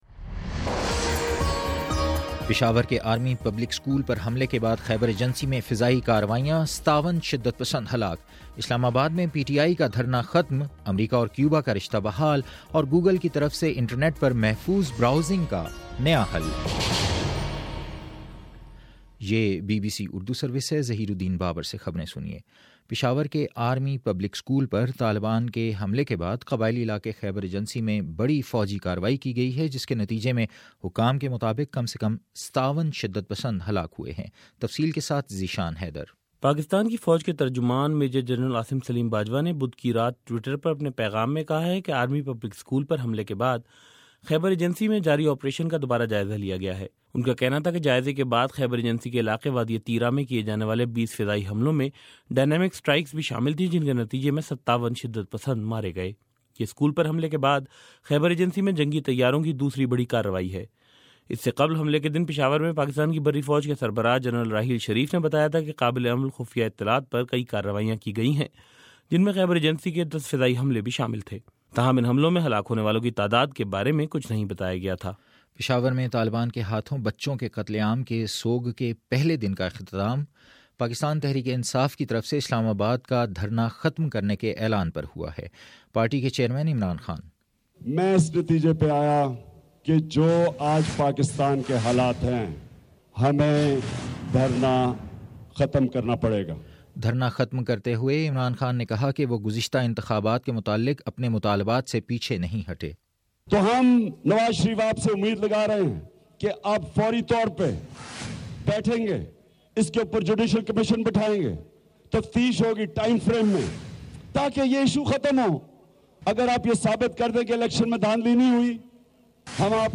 دسمبر18: صبح نو بجے کا نیوز بُلیٹن